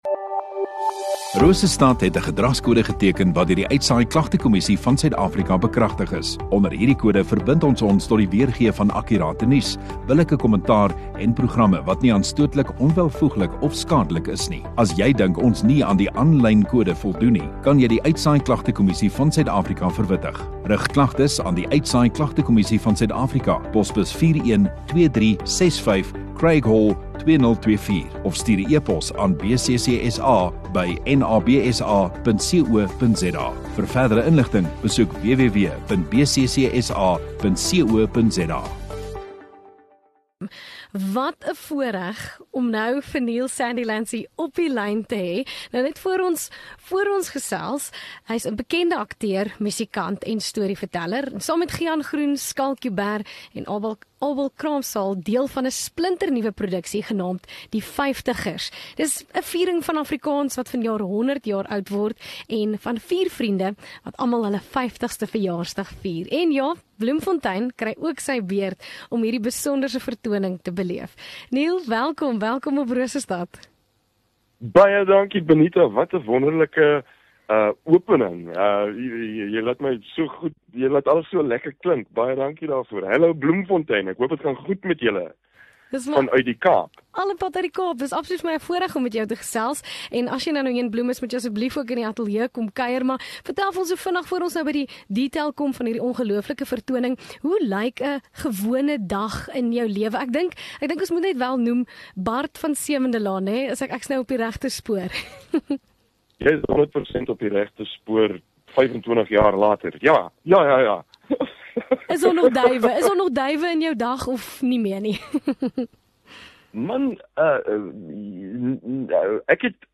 Radio Rosestad View Promo Continue Radio Rosestad Install Kunstenaar Onderhoude 10 Sep Die Vyftigers - Neil Sandilands 14 MIN Download (6.9 MB) AF SOUTH AFRICA 00:00 Playback speed Skip backwards 15 seconds